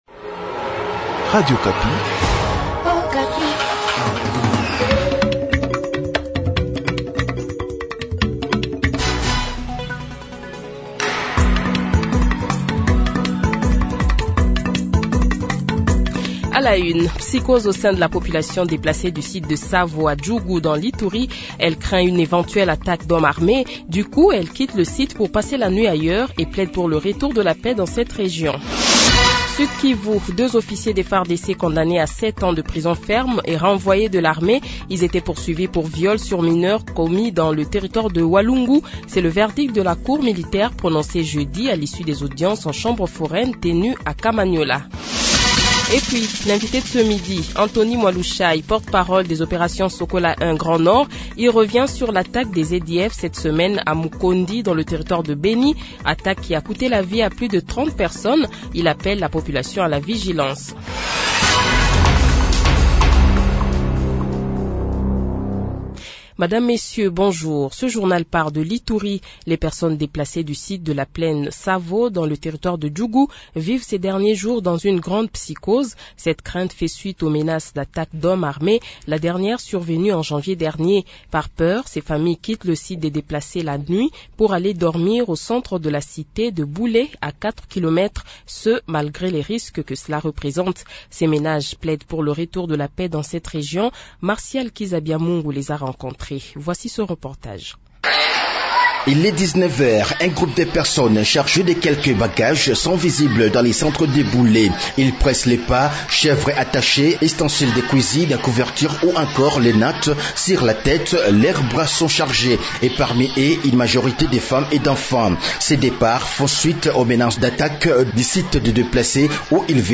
Journal Midi